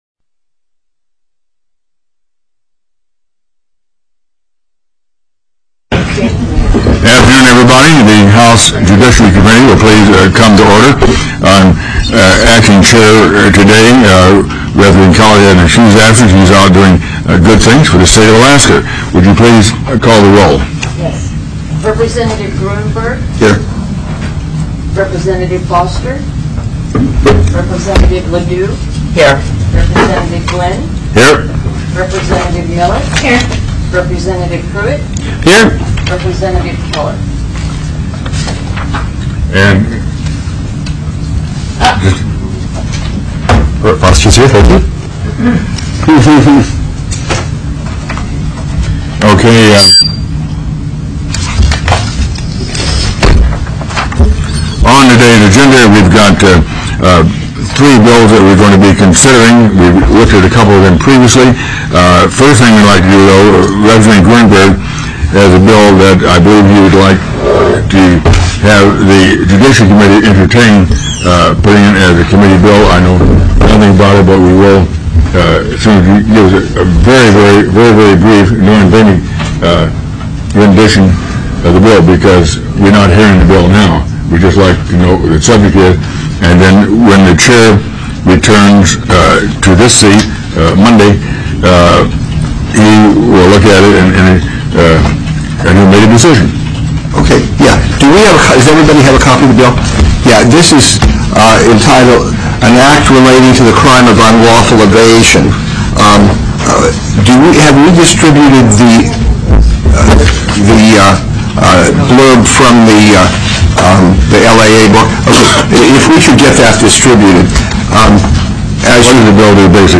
03/21/2014 01:00 PM House JUDICIARY
TELECONFERENCED